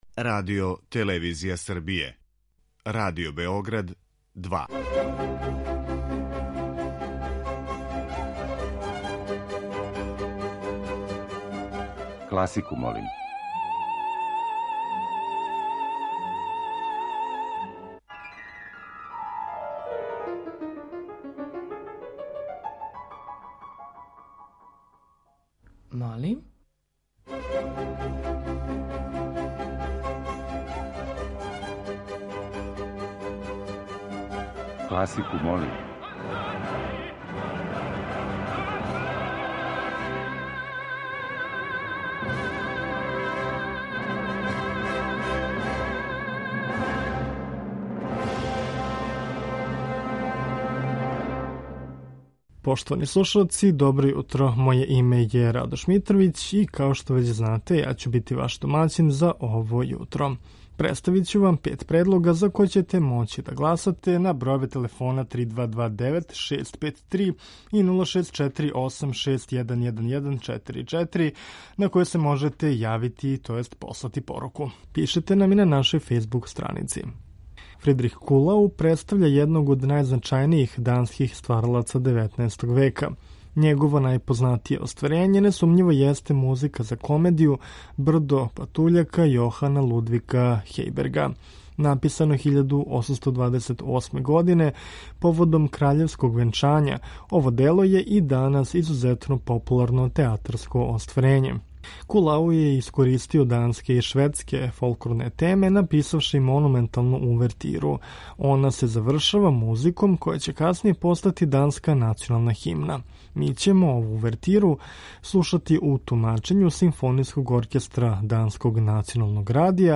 Окосница овонедељне емисије Класику, молим биће хорска дела